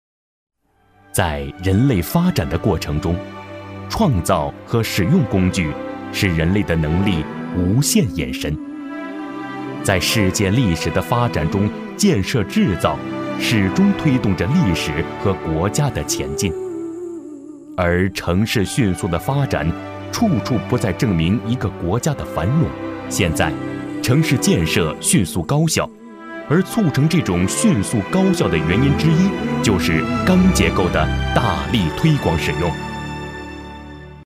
中粮集团 特 点：大气浑厚 稳重磁性 激情力度 成熟厚重